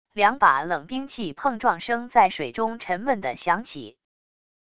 两把冷兵器碰撞声在水中沉闷地响起wav音频